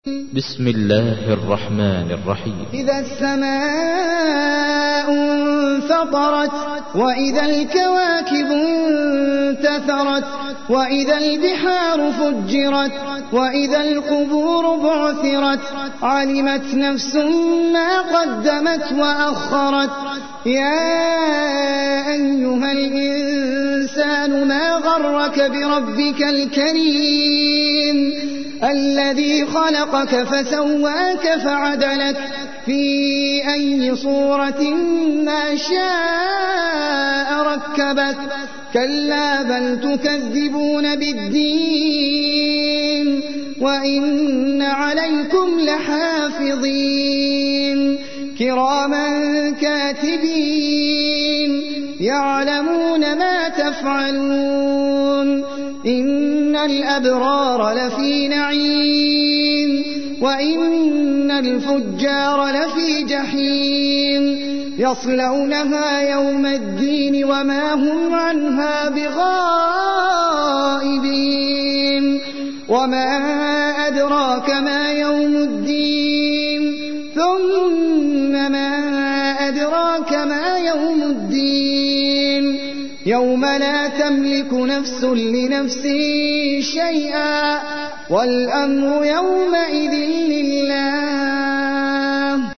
تحميل : 82. سورة الانفطار / القارئ احمد العجمي / القرآن الكريم / موقع يا حسين